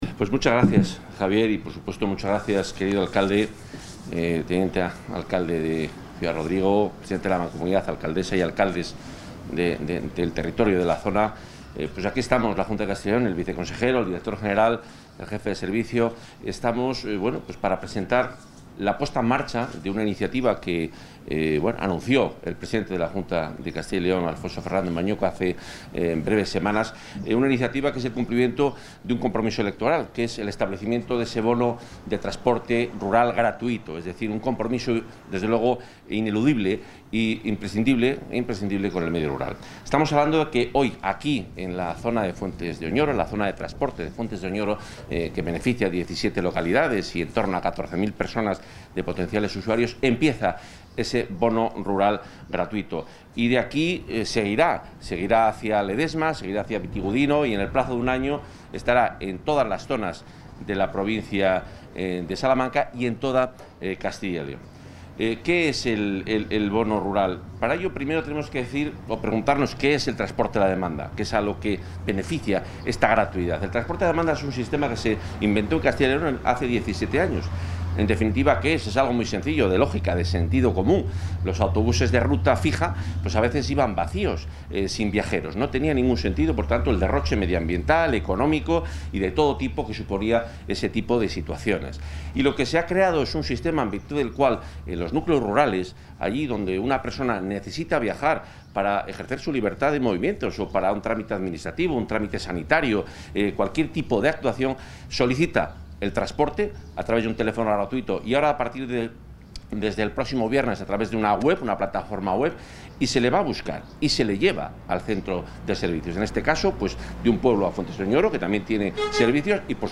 Intervención del consejero de Fomento y Medio Ambiente.